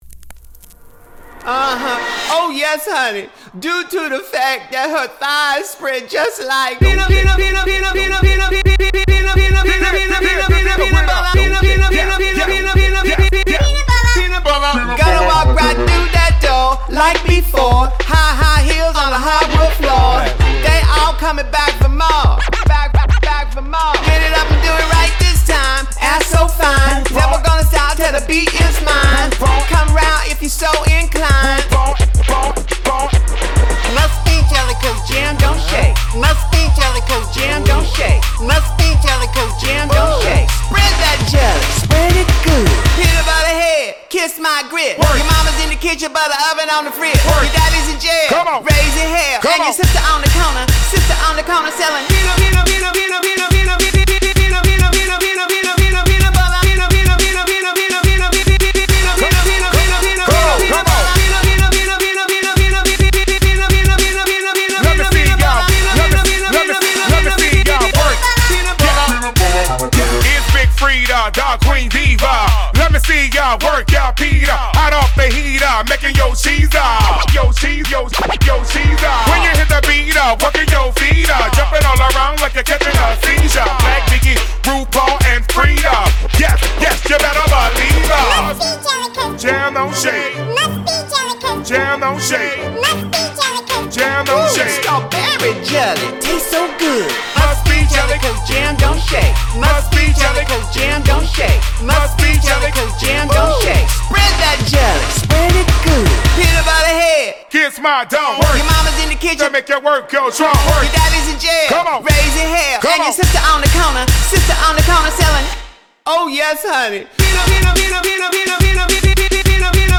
BPM53-106
Audio QualityCut From Video